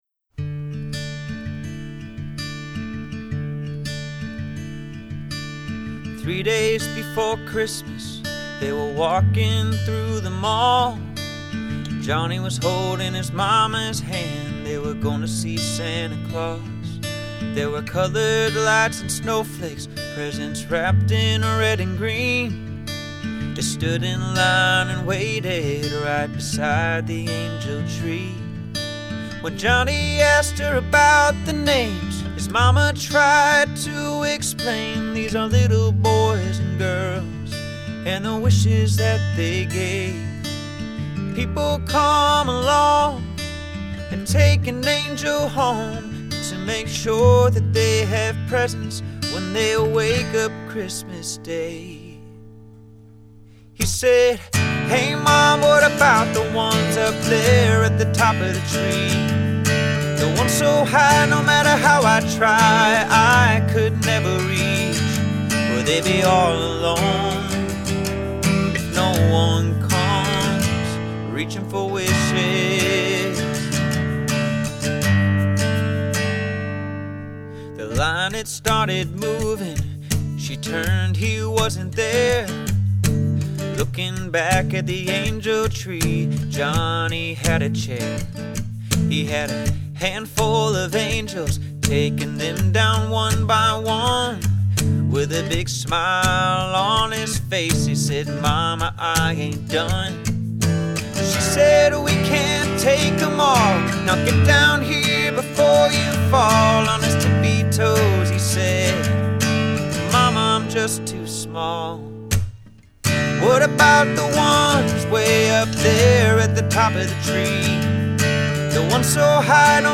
Genre: Holiday
Tags: Christmas music, uplifting